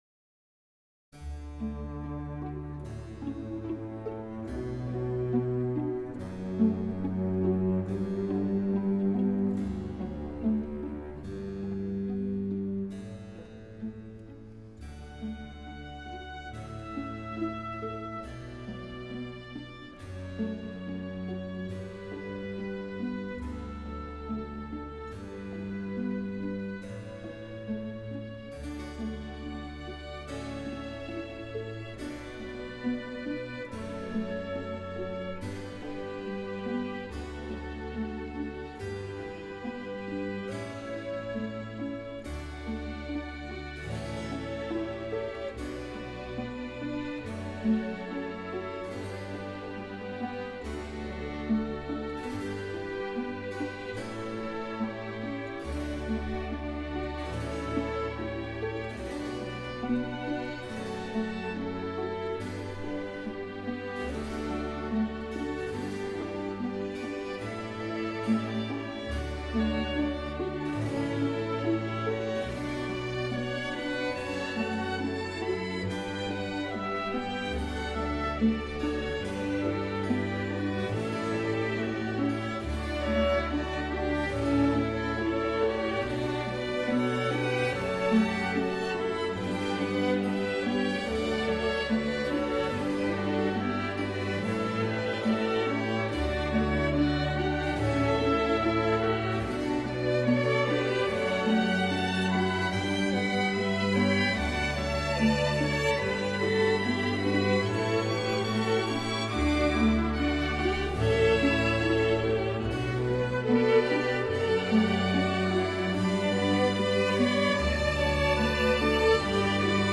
Sto ascoltando una dolce melodia… e sto rileggendo un pezzo di un film d’arte.